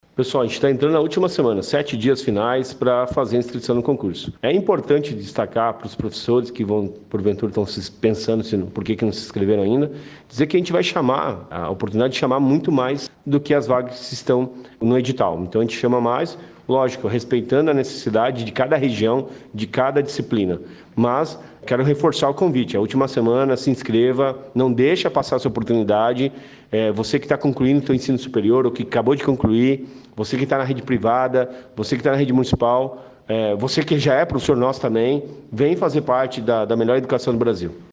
Sonora do secretário da Educação, Roni Miranda, sobre a reta final do período de inscrições para o concurso para professores